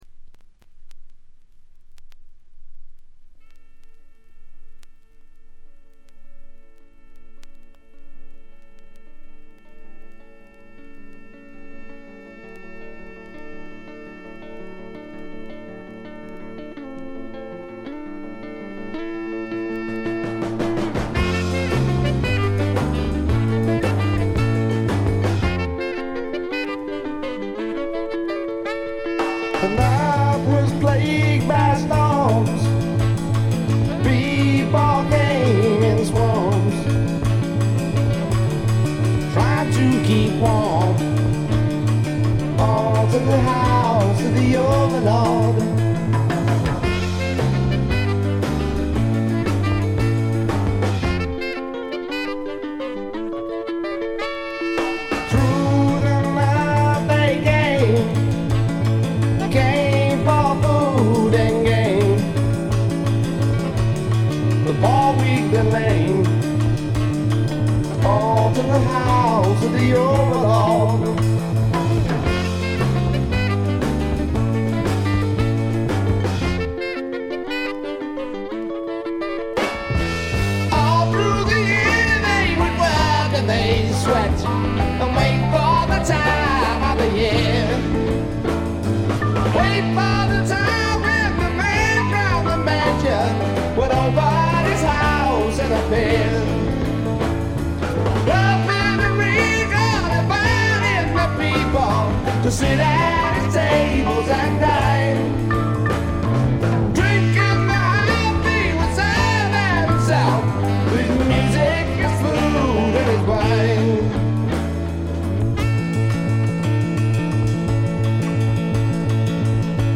サックスや木管を大胆に導入したアコースティック主体の演奏でジャズ色のある独特のフォーク・ロックを奏でる名作です。
試聴曲は現品からの取り込み音源です。